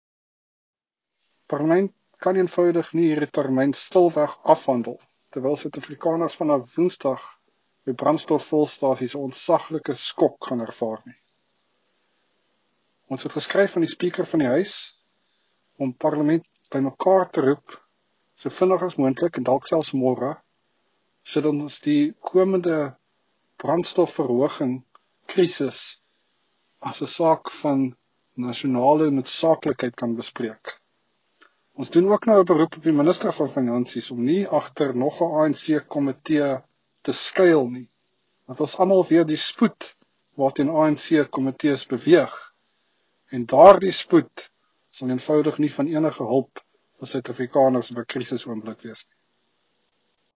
Afrikaans soundbite by Dr Mark Burke MP.